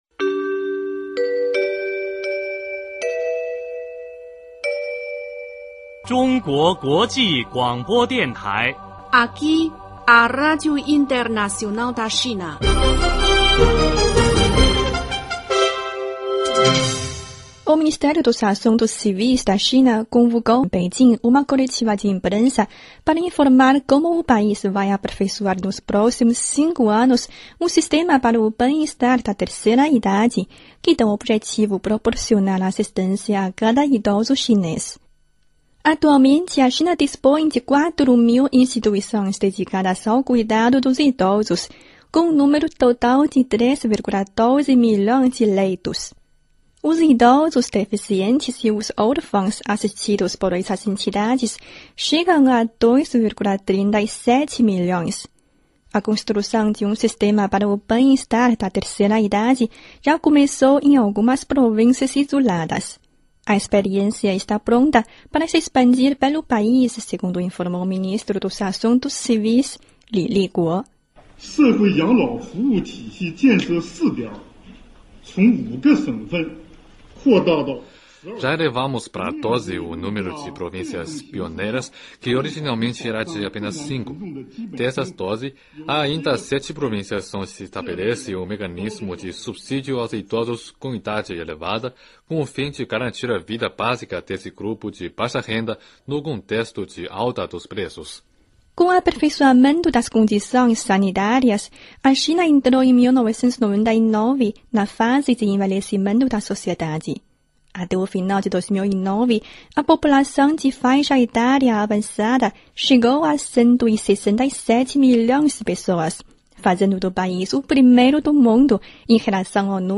Boletim da Rádio Internacional da China.